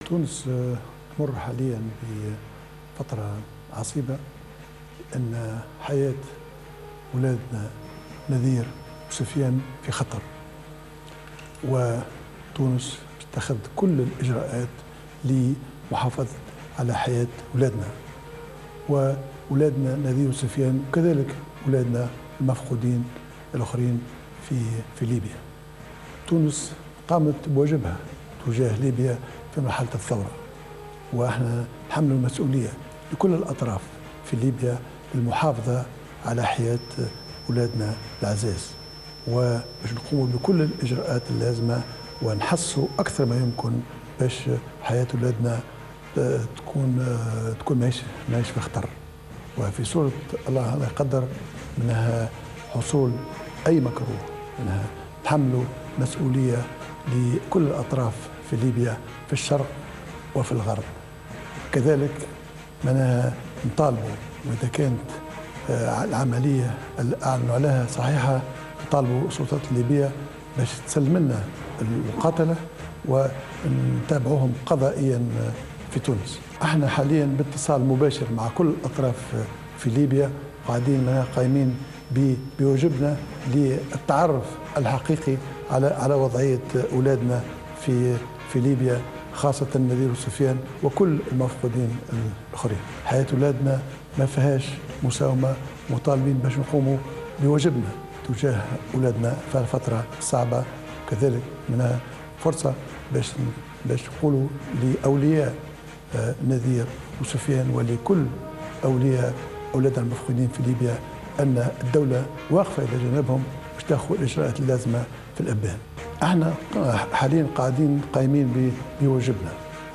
وقال رئيس الحكومة في حوار مع قناة الحوار التونسي